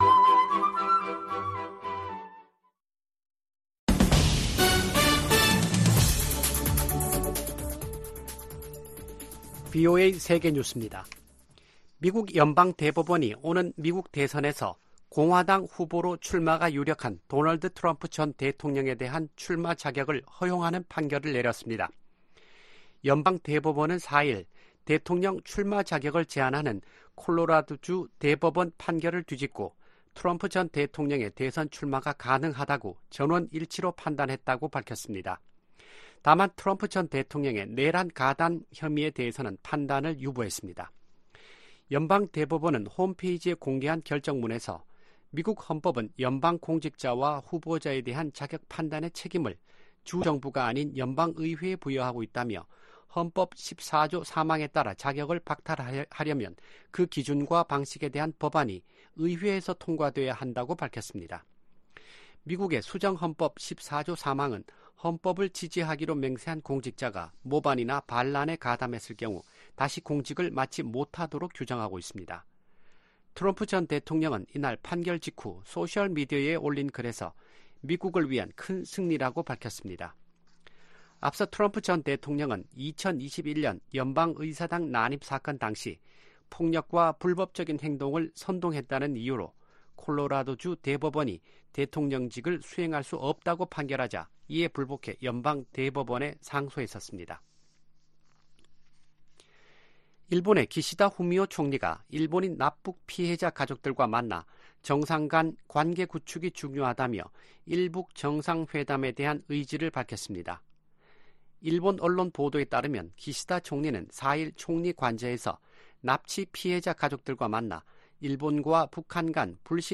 VOA 한국어 아침 뉴스 프로그램 '워싱턴 뉴스 광장' 2024년 3월 5일 방송입니다. 백악관 고위 관리가 한반도의 완전한 비핵화 정책 목표에 변함이 없다면서도 '중간 조치'가 있을 수 있다고 말했습니다. 유엔 안전보장이사회 순회 의장국 일본이 북한 핵 문제에 대한 국제적 대응에 나설 것이라고 밝혔습니다. 미 상원 중진의원이 중국 수산물 공장의 북한 강제 노동 이용은 현대판 노예 범죄라며, 관련 상품 수입 금지를 조 바이든 행정부에 촉구했습니다.